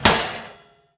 metal_impact_light_08.wav